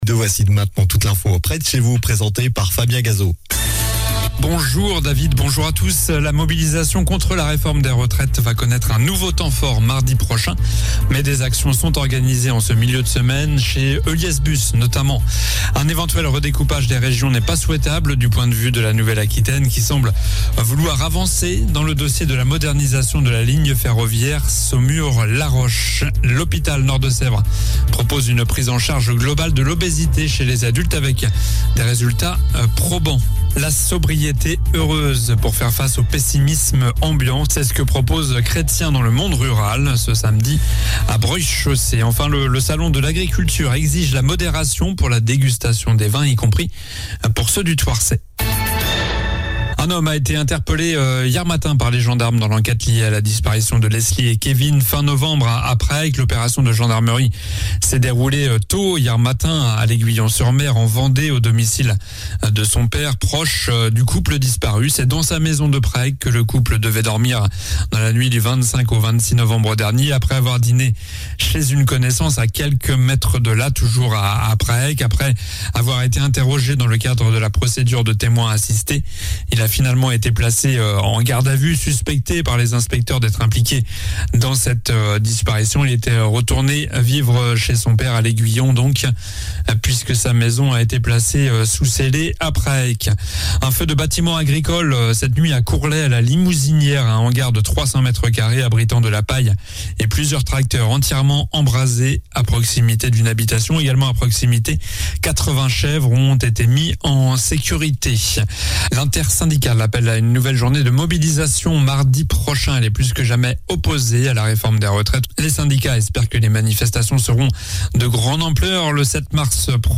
Journal du mercredi 1er mars (midi)